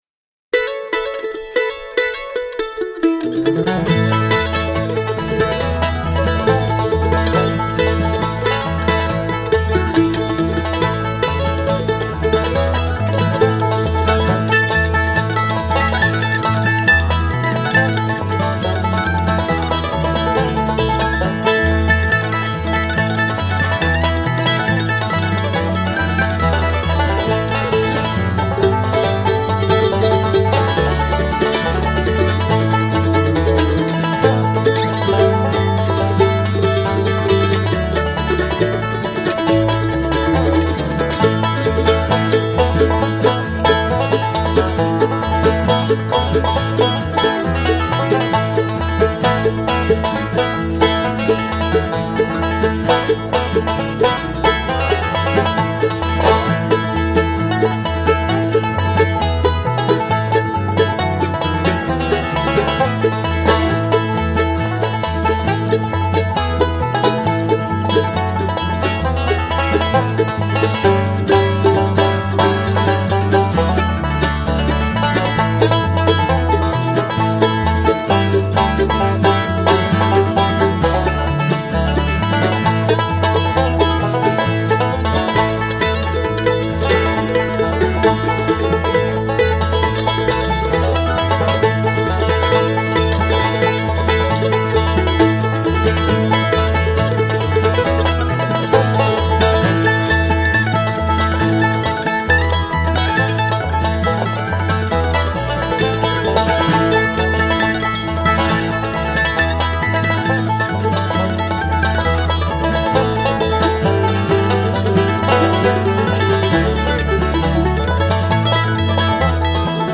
Great sound, great playing, well balanced.